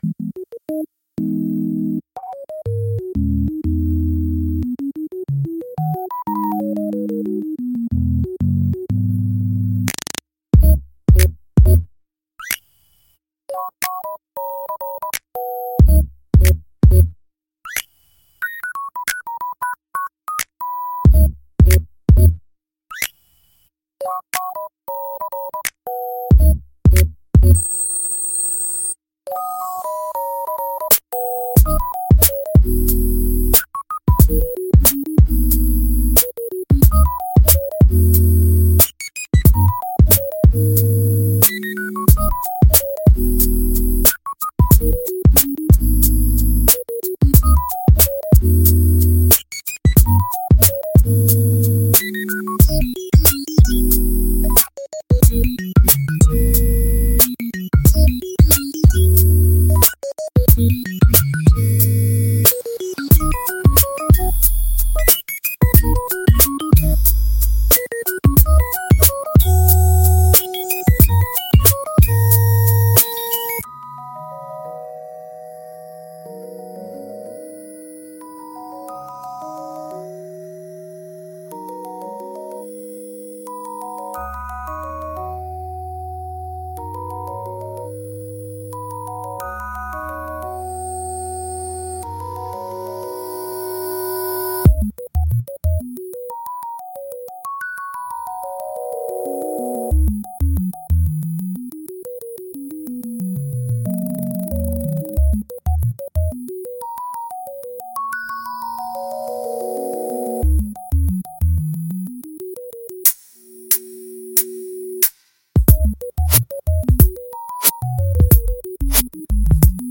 聴く人の感覚を刺激し、緊張感や好奇心を喚起しながら、独自の雰囲気を強調する効果があります。